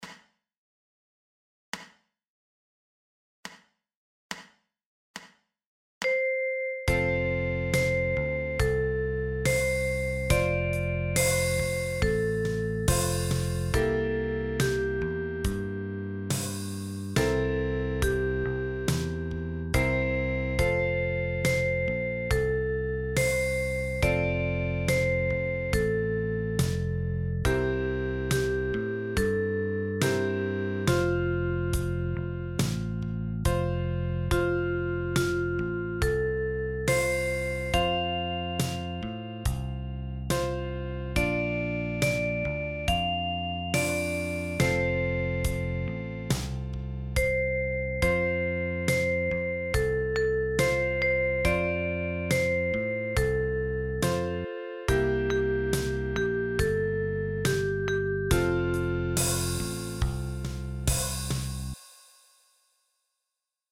Für Altblockflöte in F.